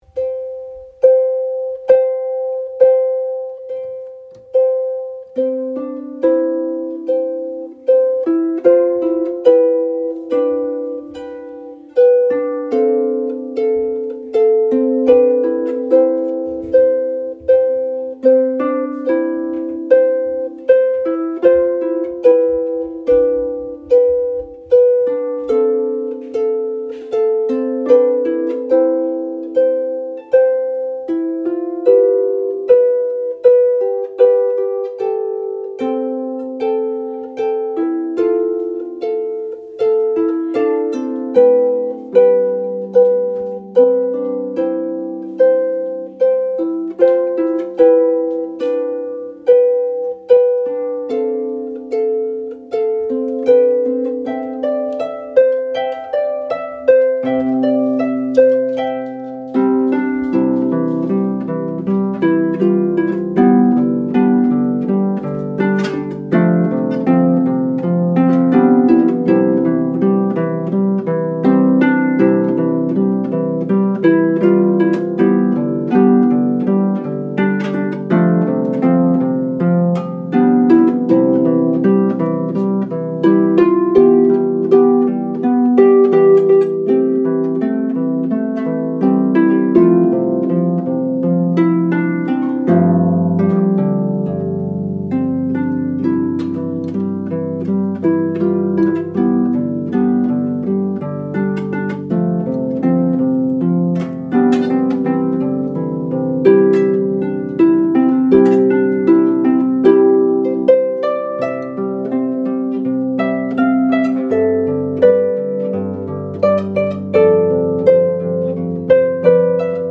• Solo harpist with a wide-ranging repertoire